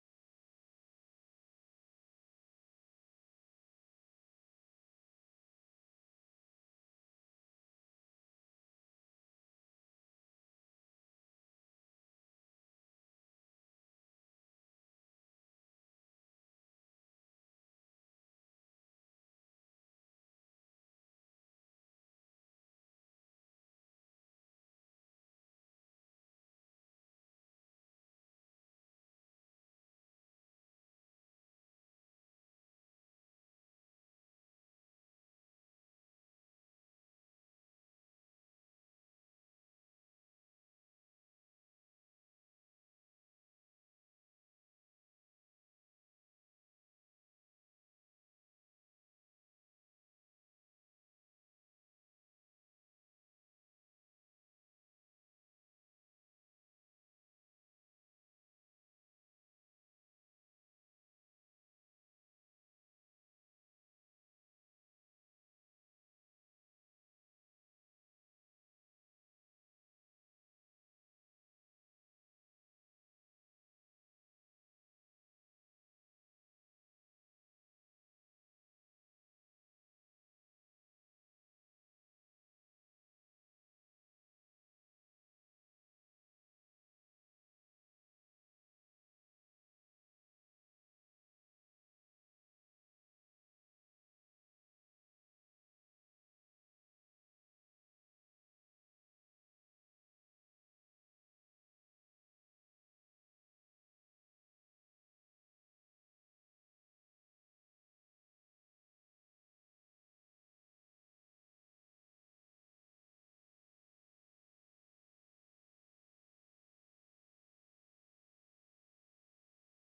Sermons by Versailles Christian Church